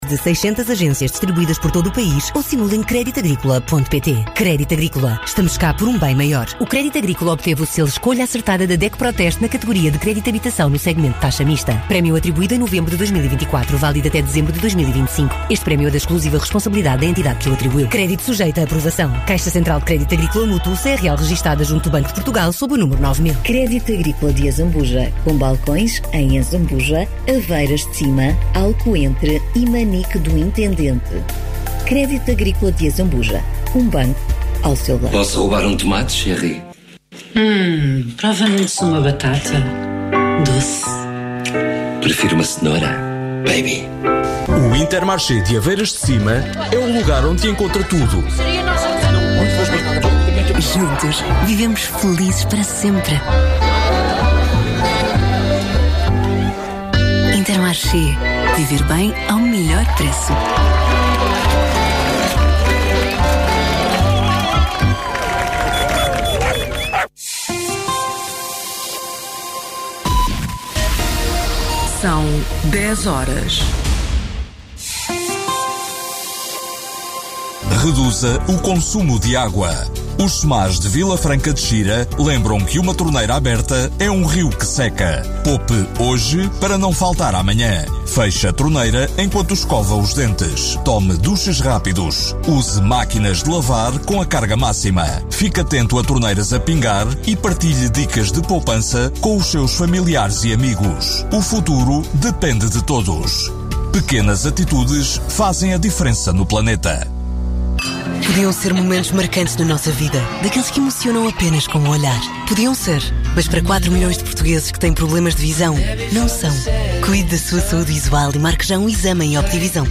Com diferentes perspetivas sobre a atualidade política portuguesa, o debate centra-se nos temas que marcam a agenda: a saúde e a habitação.